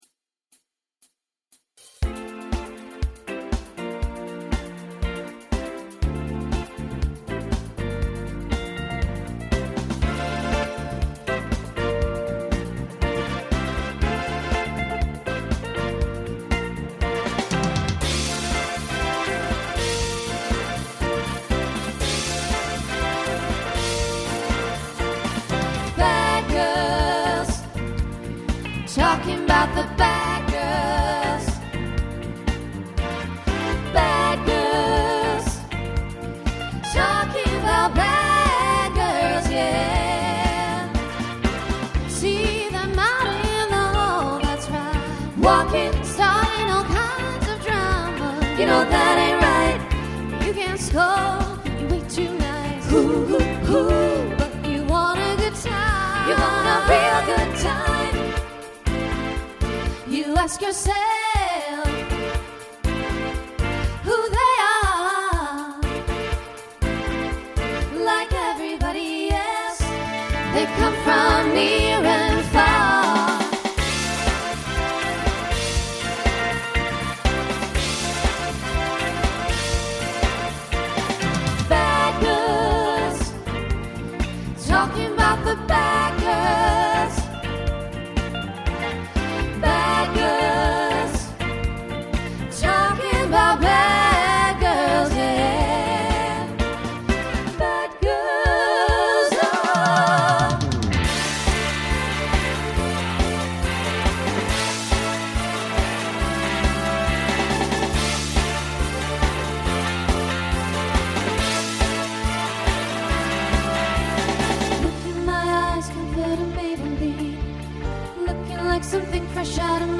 Voicing SSA Instrumental combo Genre Disco , Pop/Dance